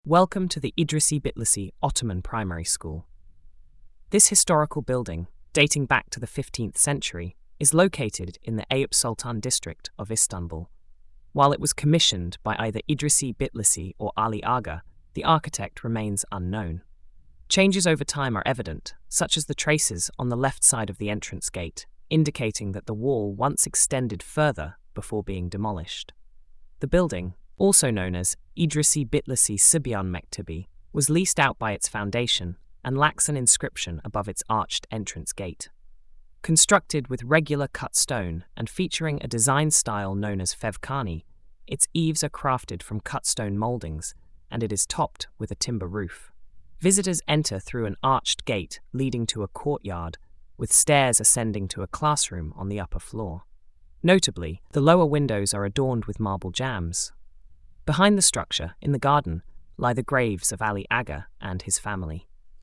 Audio Narration: